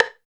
62 LO STIK-L.wav